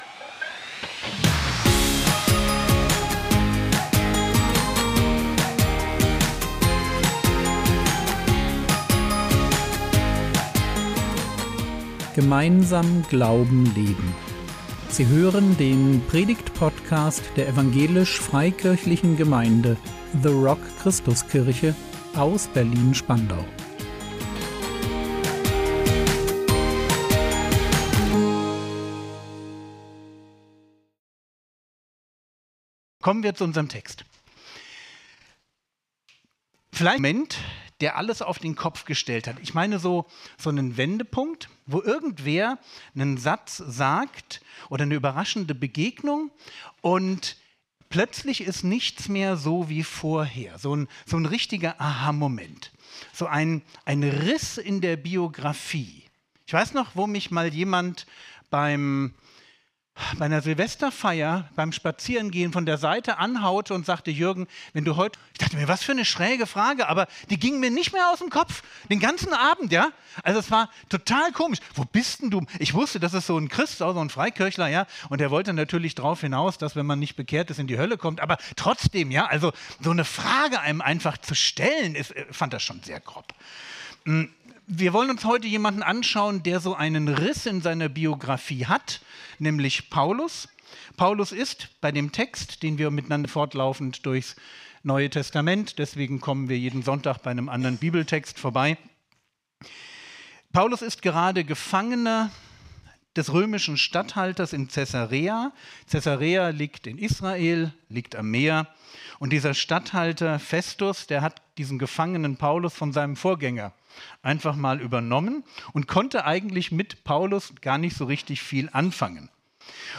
Ein Riss in der Biografie | 15.03.2026 ~ Predigt Podcast der EFG The Rock Christuskirche Berlin Podcast